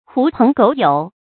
狐朋狗友 注音： ㄏㄨˊ ㄆㄥˊ ㄍㄡˇ ㄧㄡˇ 讀音讀法： 意思解釋： 將狐狗引為朋友。比喻品行不端的朋友。